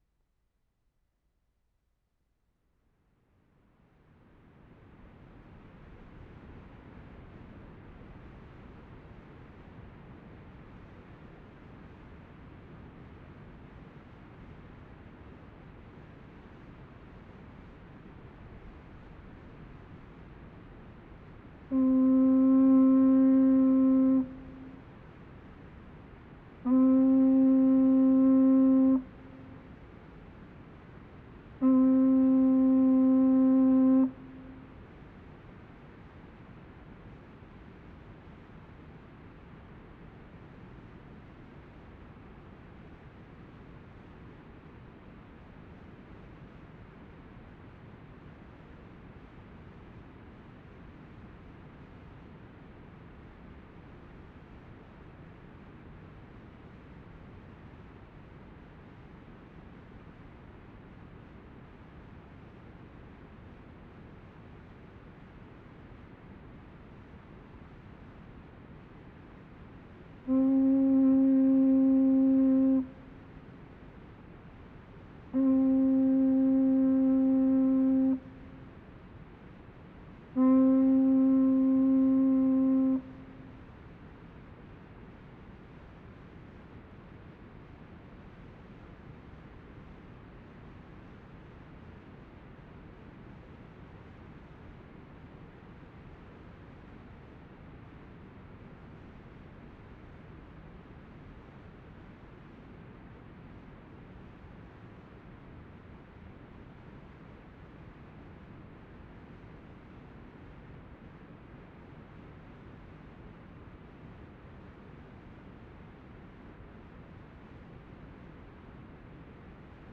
WORLD SOUNDSCAPE PROJECT TAPE LIBRARY
Wensleydale, Yorkshire May 11, 12/75
SECOND SET OF HORNBLOWS at Aysgarth Falls 3 sets of 3 (see also reel 64)
3.  MORE HORNBLOWS ( one set of 3)
2, 3. Note reverb can still be heard over the masking effect of the waterfalls.